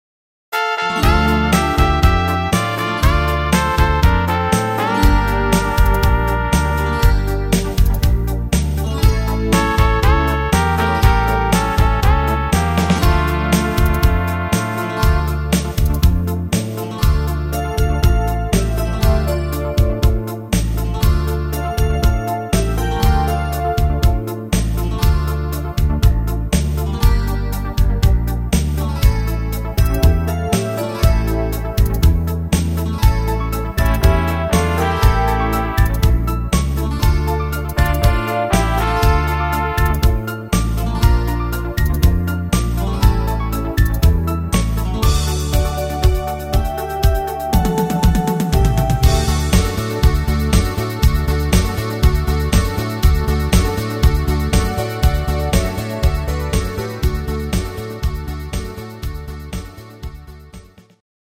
Rhythmus  Beguine
Art  Schlager 90er, Deutsch